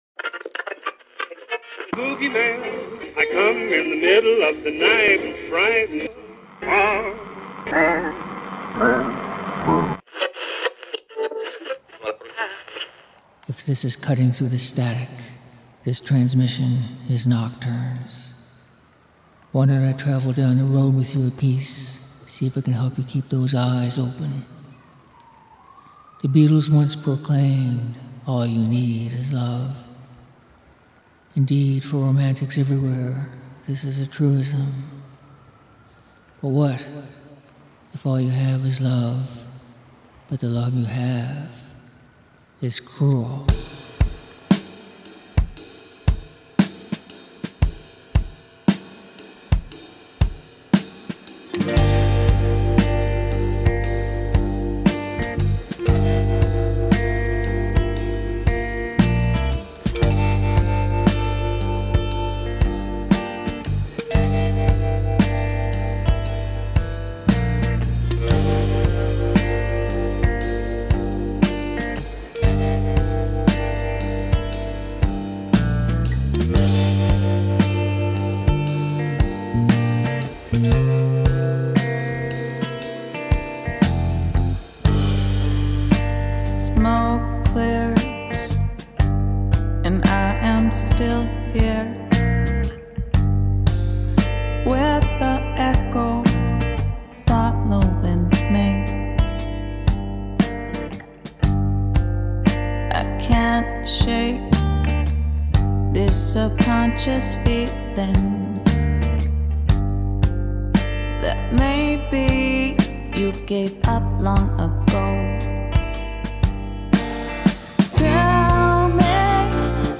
Music For Nighttime Listening